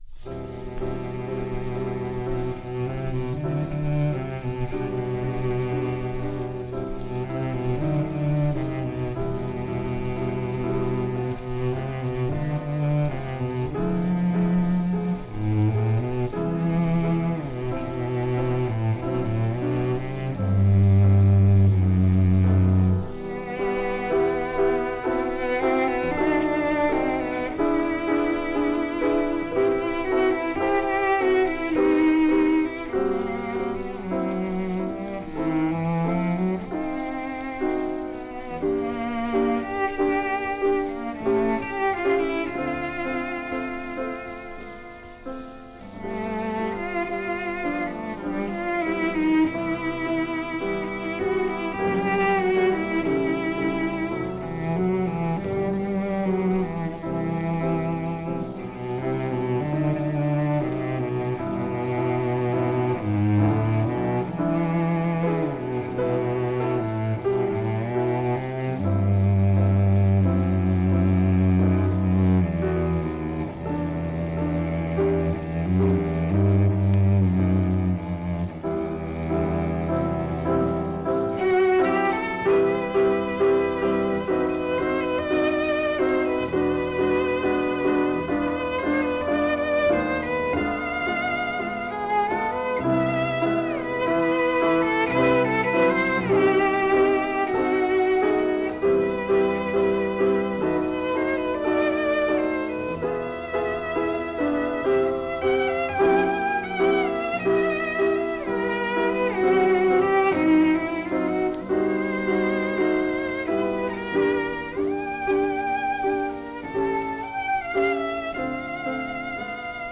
More Contemporary music of the 20th Century this week.